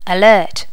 Additional sounds, some clean up but still need to do click removal on the majority.
alert.wav